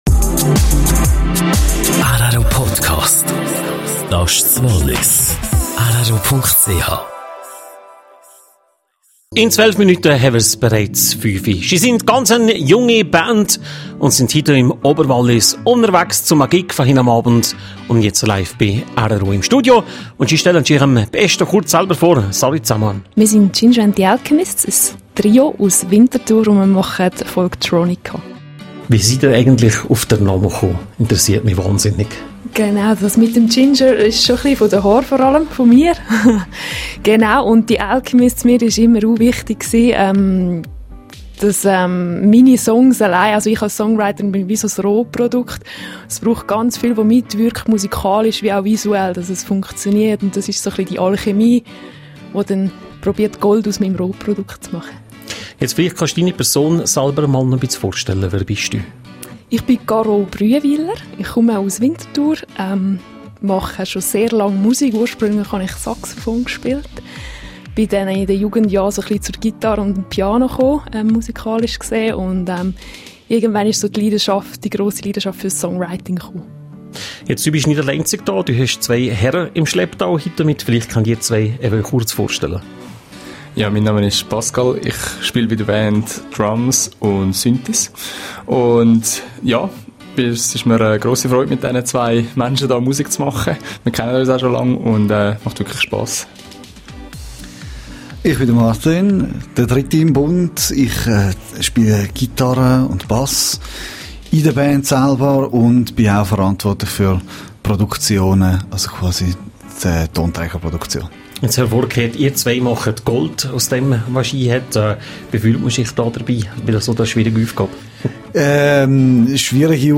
Vor ihrem Auftritt in Fiesch besuchten Ginger and the alchemists am Samstag das Radiostudio in Visp und stellten sich der rro-Community vor.